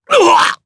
Phillop-Vox_Damage_jp_03.wav